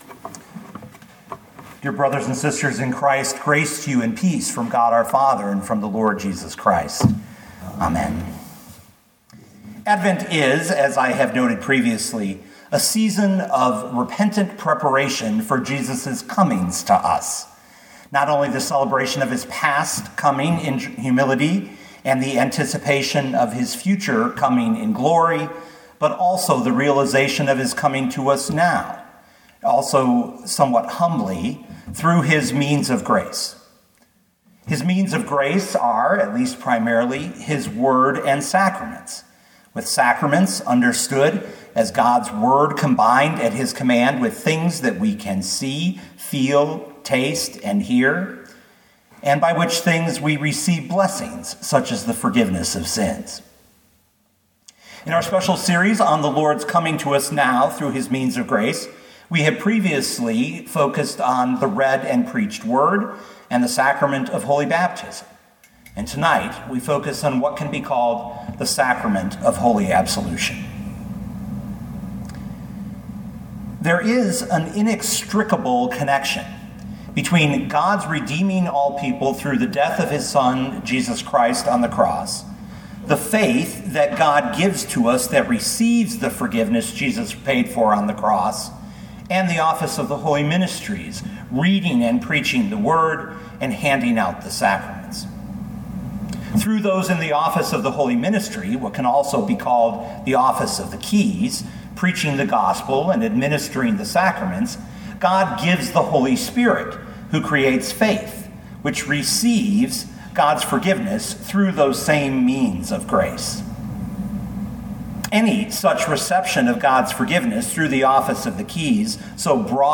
Matthew 16:13-20 Listen to the sermon with the player below, or, download the audio.